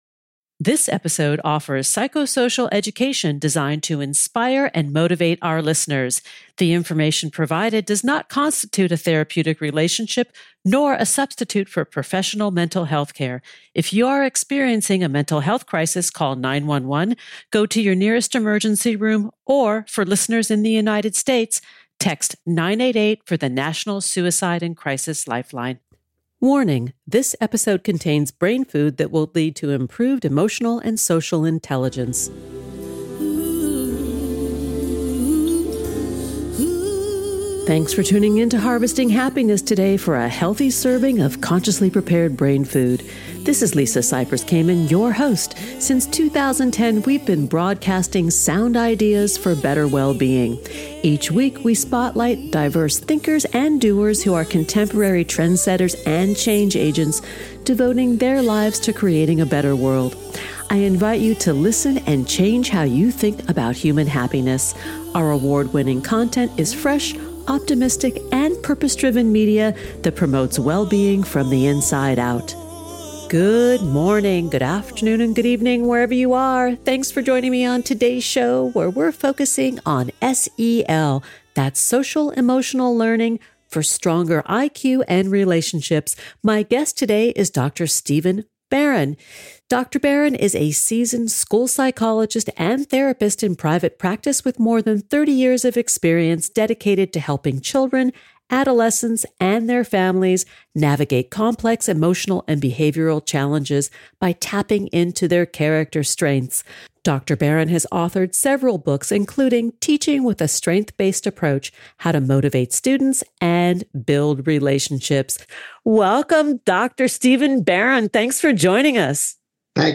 We had a great conversation discussing how teachers can be positive agents of change for their students, enhancing their emotional, social, and academic development.